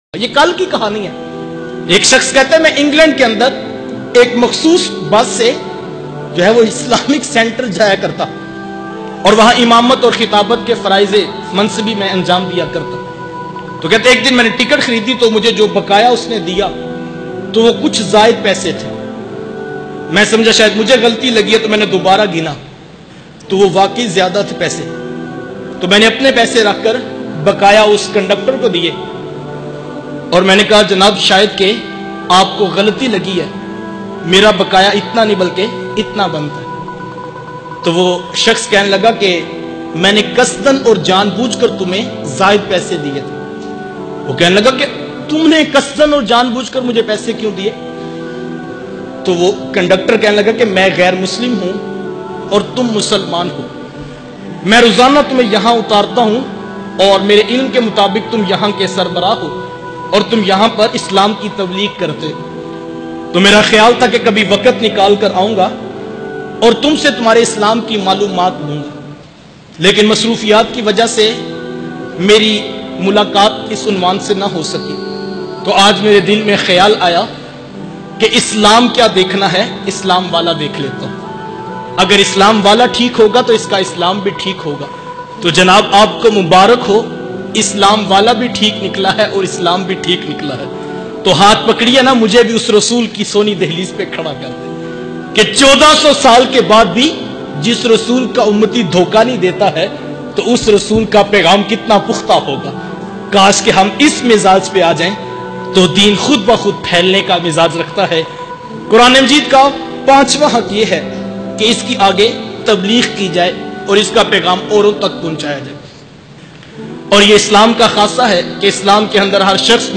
Ghair muslim ka qabool e islam bayan mp3